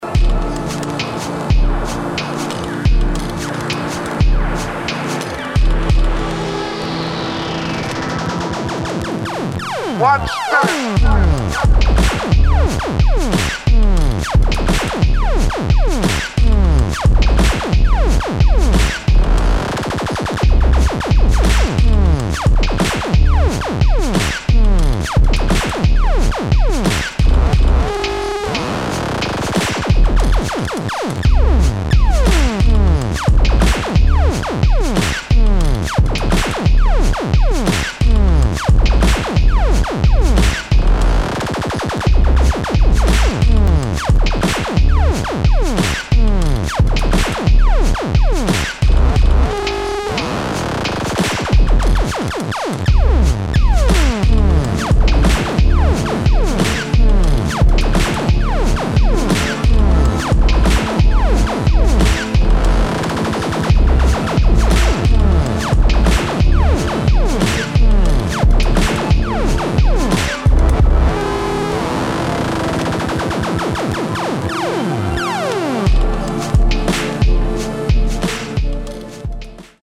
[ DOWNBEAT / ELECTRONIC / DUBSTEP ]